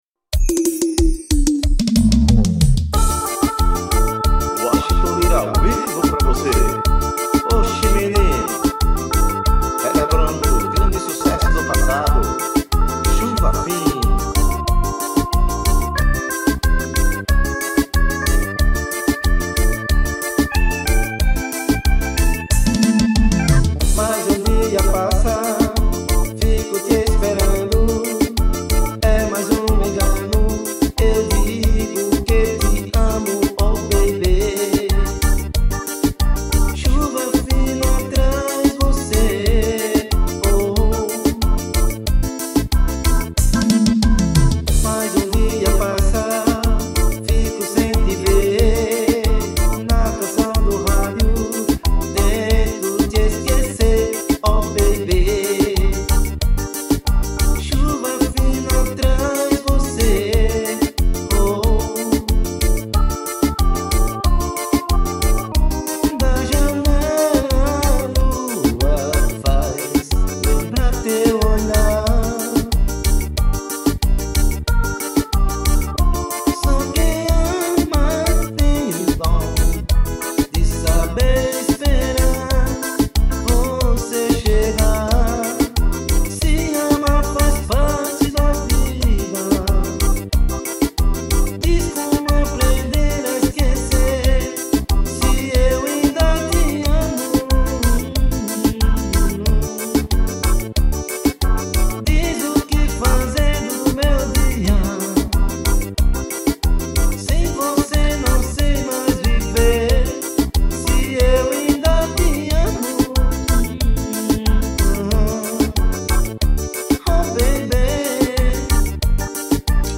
AO VIVO